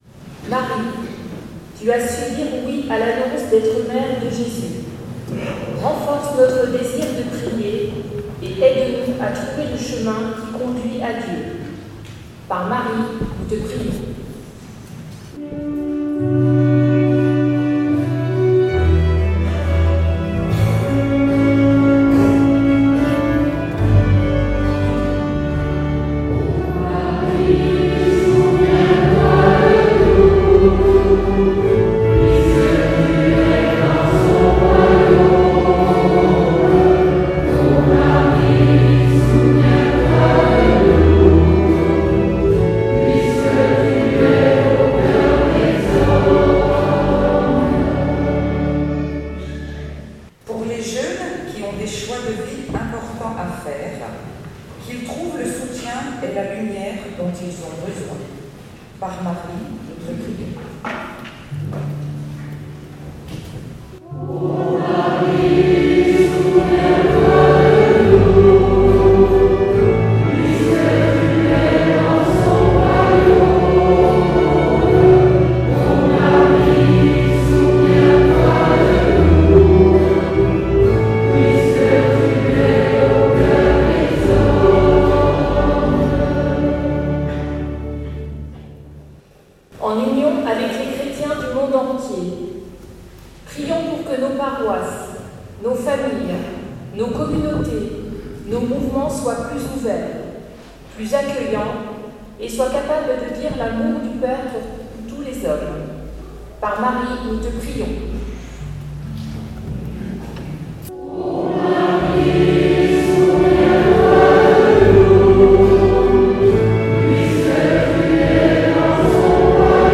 Messe Rosaire 2019
Prière Universelle : O Marie souviens-toi de nous